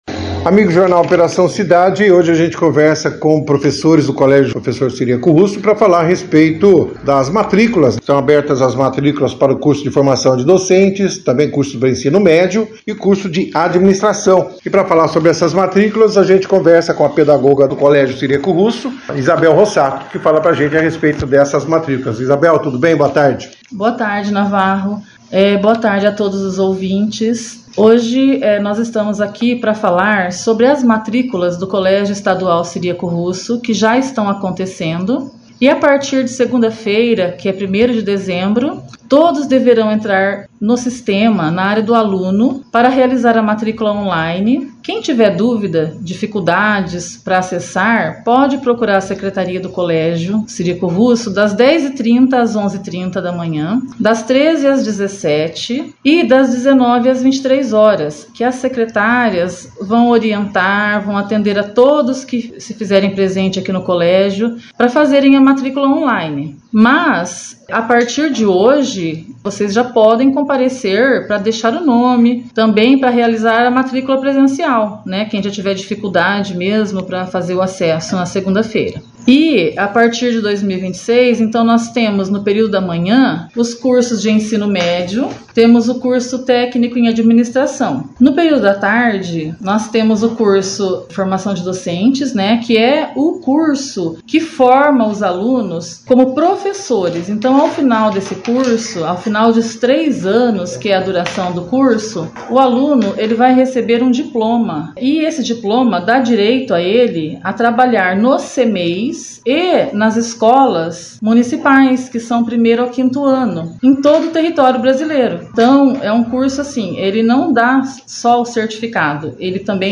Na 2ª edição do Jornal Operação Cidade desta quinta-feira,27, recebemos a equipe do Colégio Estadual Cyriaco Russo (foto).